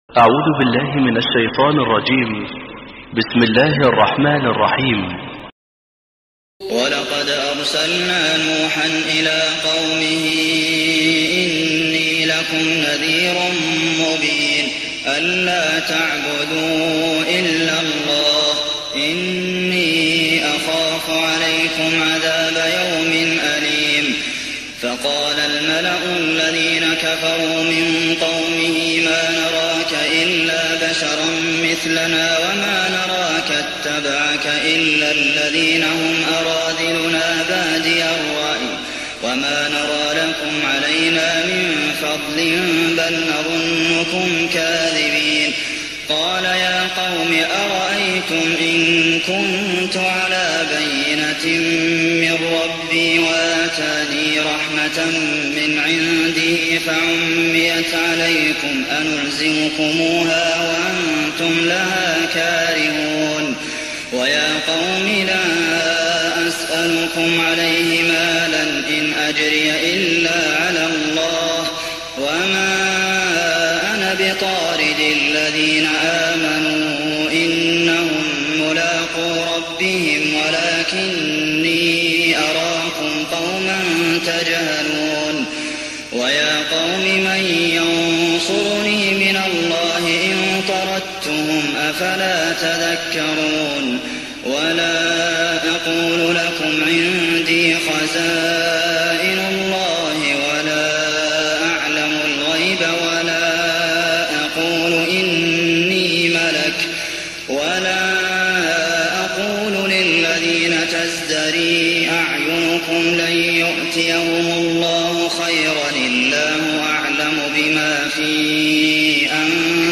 تهجد رمضان 1419هـ من سورة هود (25-123) Tahajjud Ramadan 1419H from Surah Hud > تراويح الحرم النبوي عام 1419 🕌 > التراويح - تلاوات الحرمين